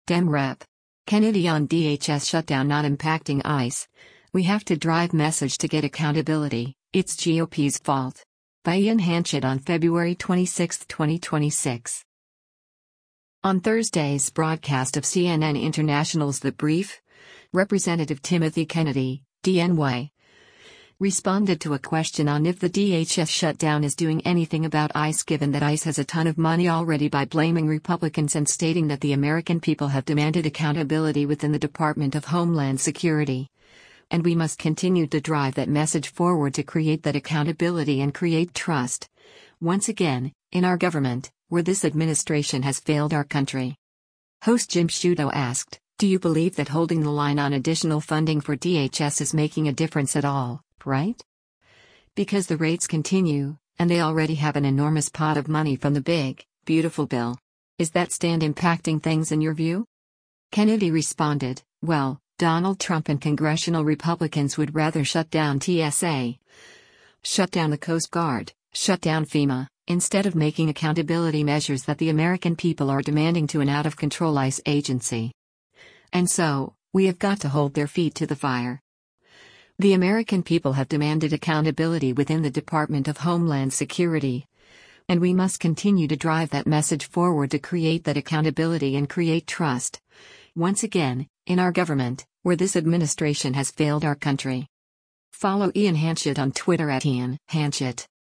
On Thursday’s broadcast of CNN International’s “The Brief,” Rep. Timothy Kennedy (D-NY) responded to a question on if the DHS shutdown is doing anything about ICE given that ICE has a ton of money already by blaming Republicans and stating that “The American people have demanded accountability within the Department of Homeland Security, and we must continue to drive that message forward to create that accountability and create trust, once again, in our government, where this administration has failed our country.”
Host Jim Sciutto asked, “Do you believe that holding the line on additional funding for DHS is making a difference at all, right? Because the raids continue, and they already have an enormous pot of money from the big, beautiful bill. Is that stand impacting things in your view?”